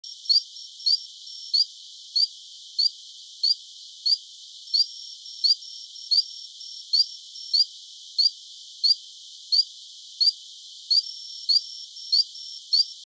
8231Ameerega simulans.mp3